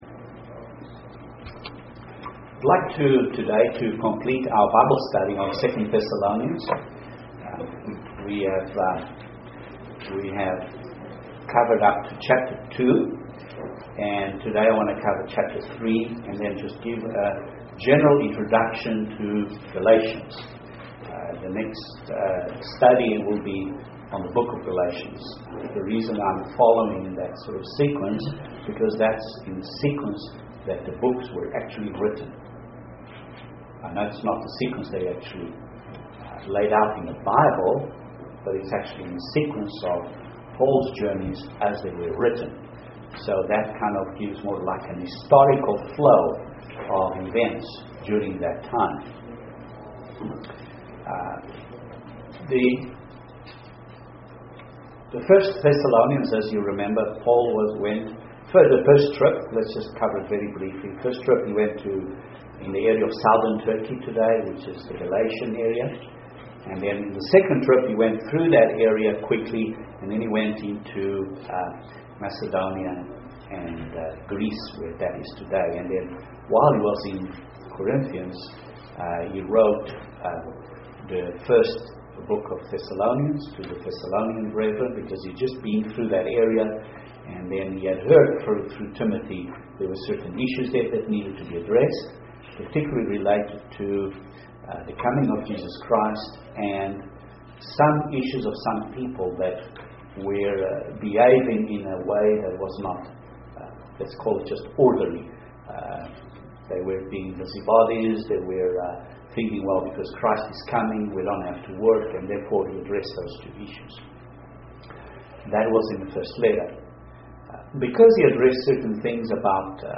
Completion of the Bible Study on 2 Thessalonians 3